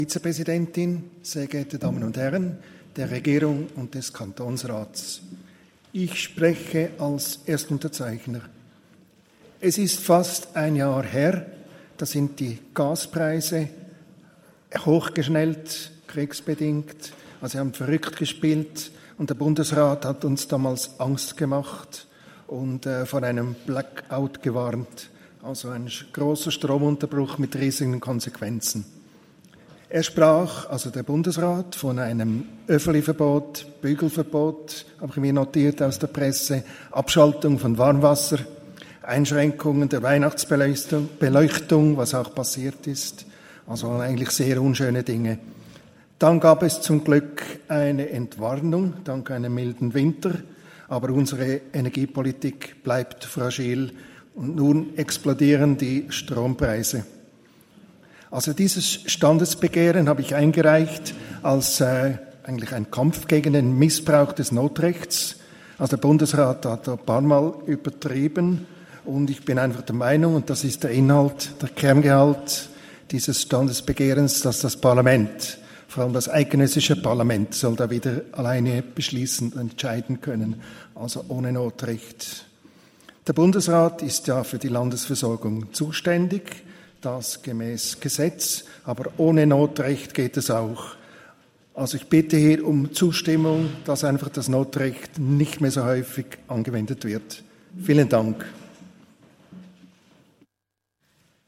Session des Kantonsrates vom 18. bis 20. September 2023, Herbstsession
18.9.2023Wortmeldung